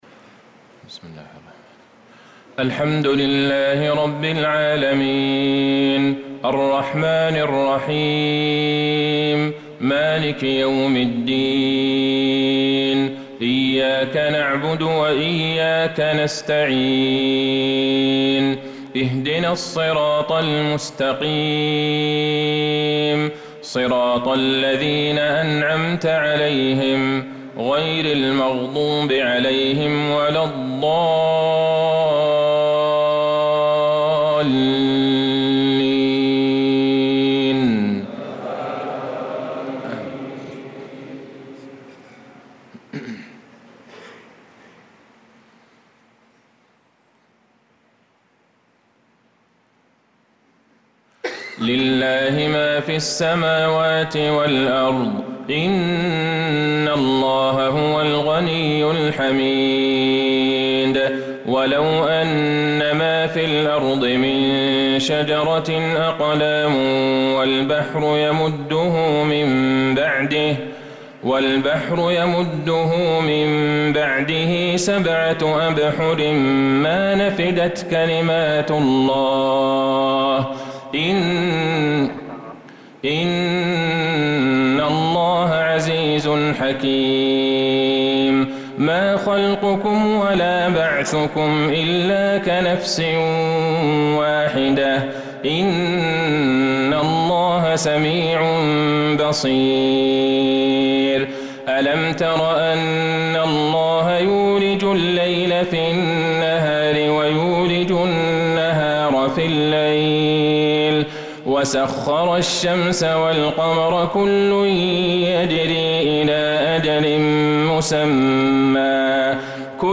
صلاة العشاء للقارئ عبدالله البعيجان 18 محرم 1446 هـ